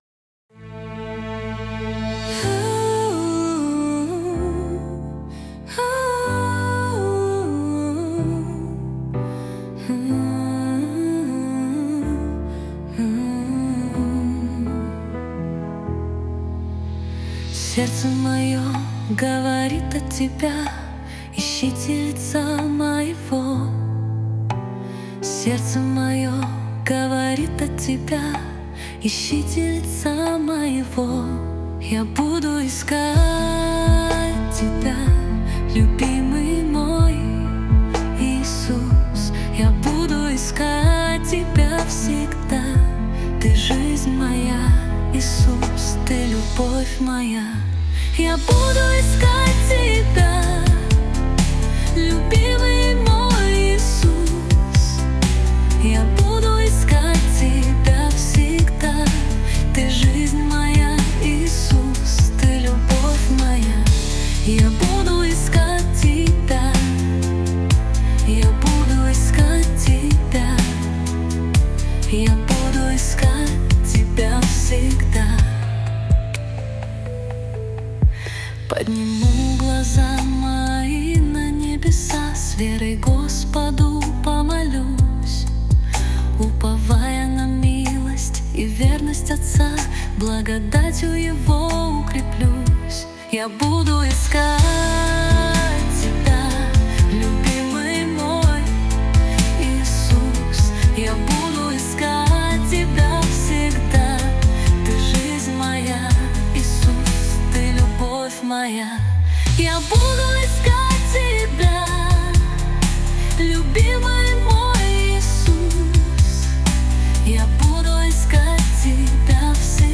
песня ai
342 просмотра 1014 прослушиваний 66 скачиваний BPM: 125